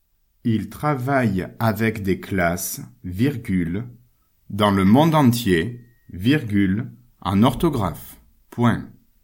Vous retrouvez ici les phrases à écrire, lues par le maître.